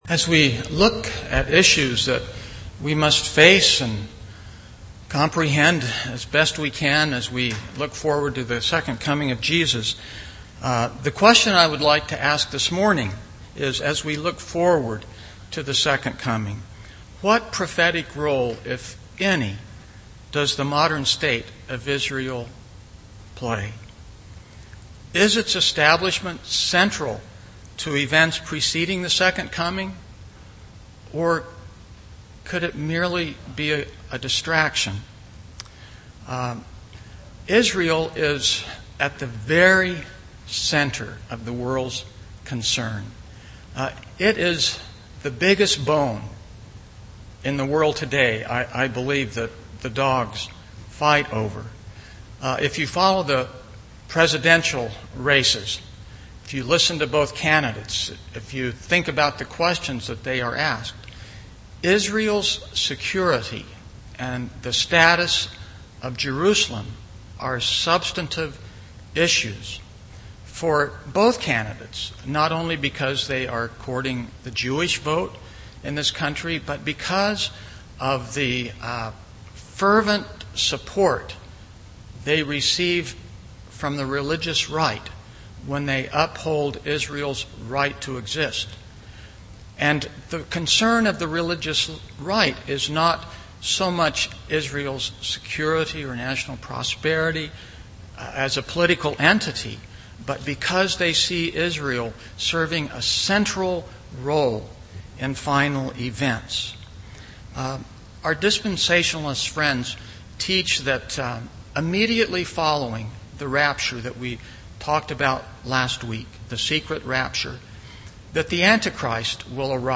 Seventh-day Adventist Church